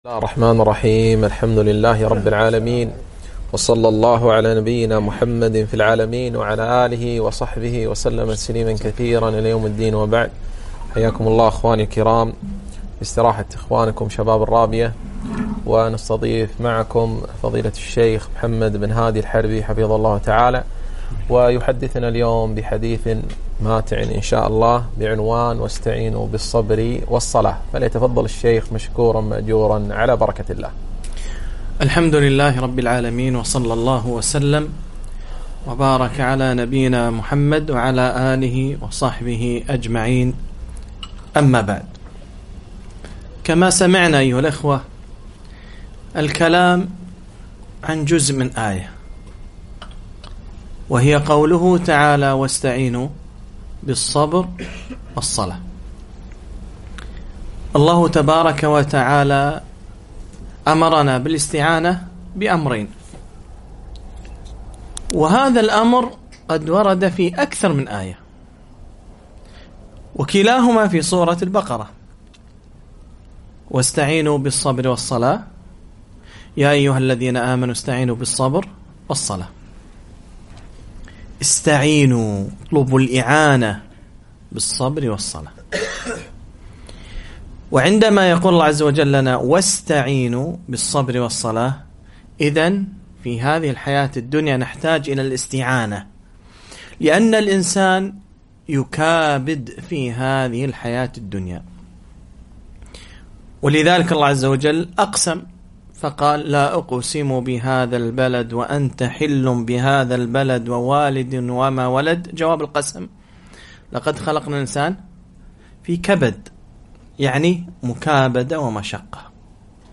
محاضرة - (واستعينوا بالصبر والصلاة)